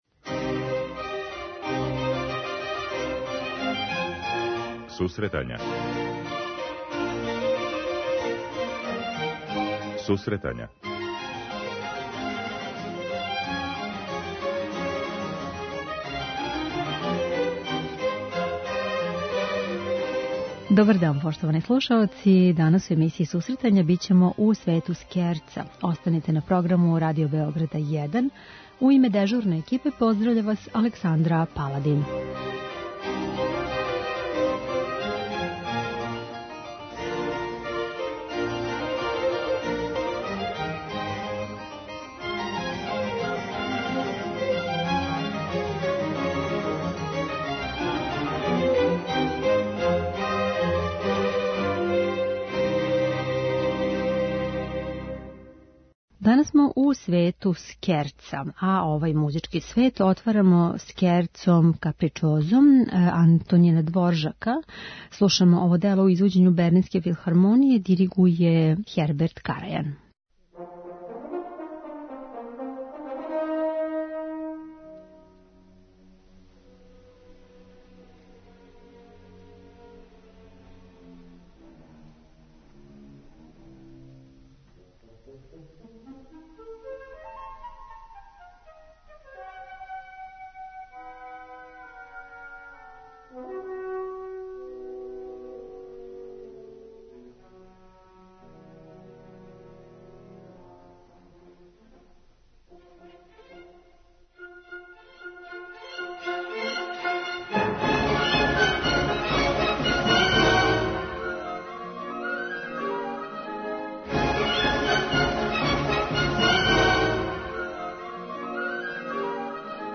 преузми : 10.25 MB Сусретања Autor: Музичка редакција Емисија за оне који воле уметничку музику.